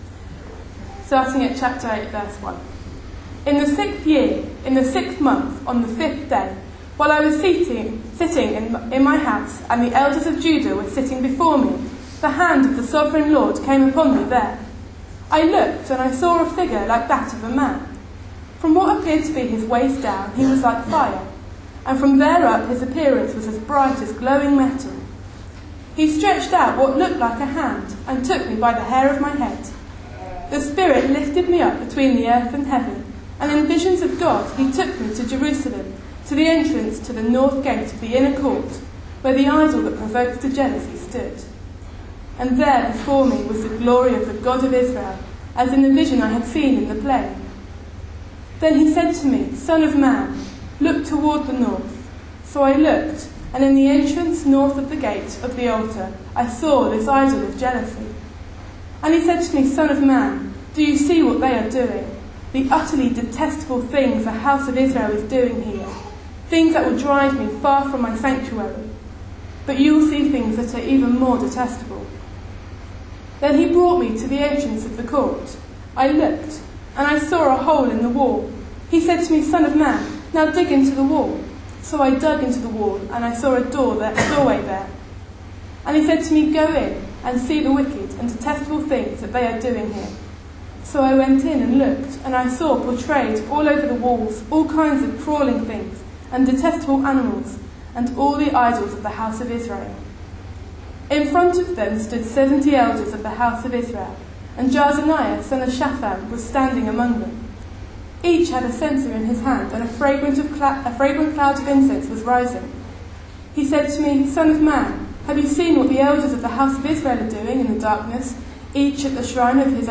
A sermon preached on 15th June, 2014, as part of our Ezekiel series.